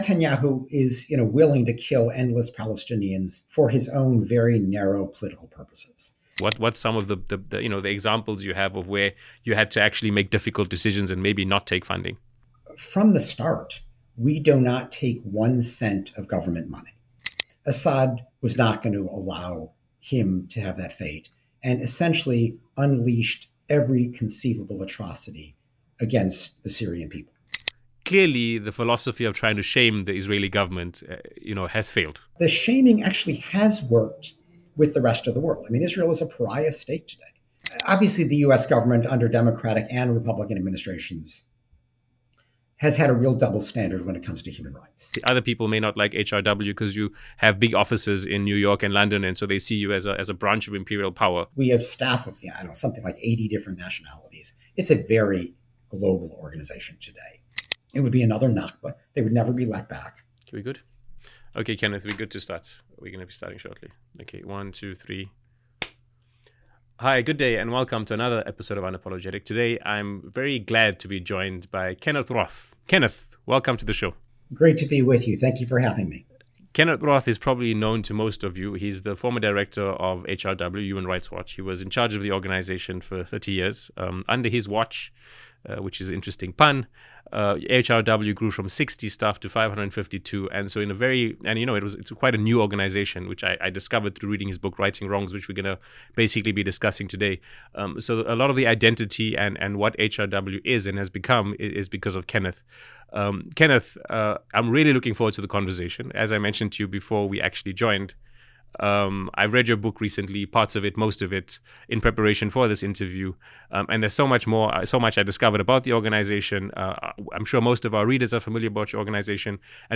Kenneth Roth, former executive director of Human Rights Watch, joins UNAPOLOGETIC to discuss his new book Righting Wrongs.